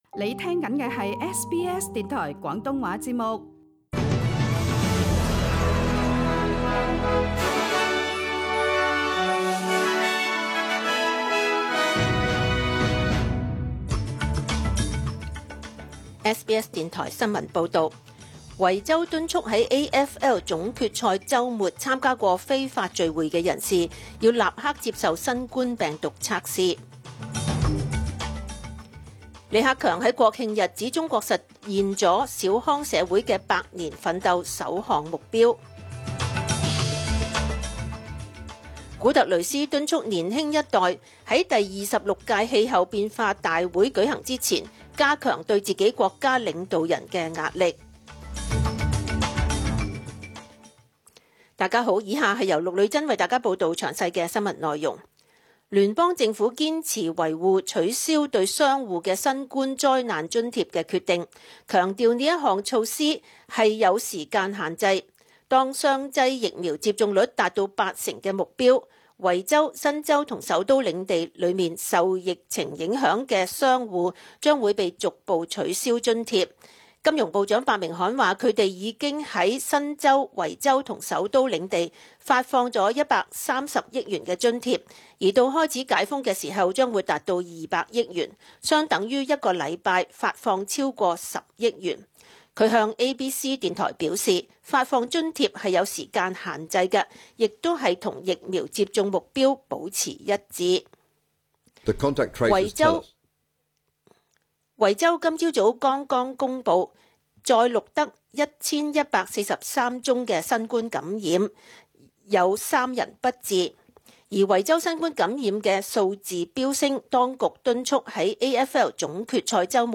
SBS 中文新聞（十月一日）